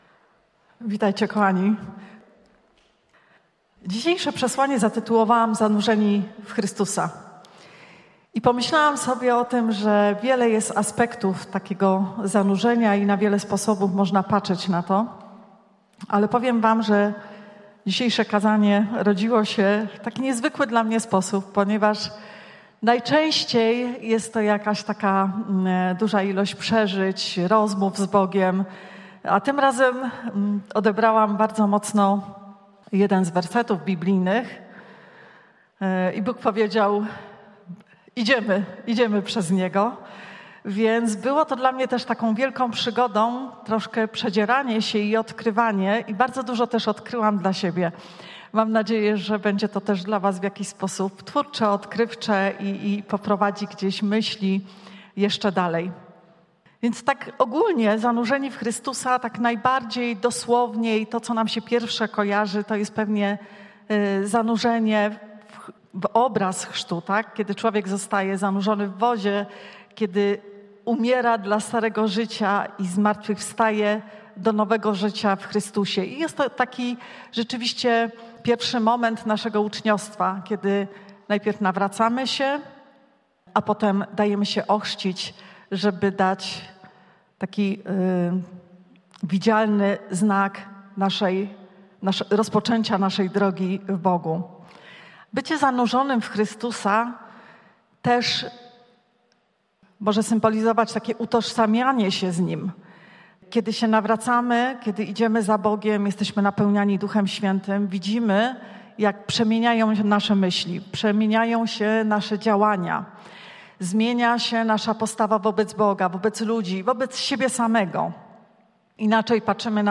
Zanurzeni w Chrystusa Pytania do przemyślenia po kazaniu: 1) Jak ci smakuje życie? 2) Jak bardzo potrafisz na dzień dzisiejszy zrezygnować ze swoich dzieł?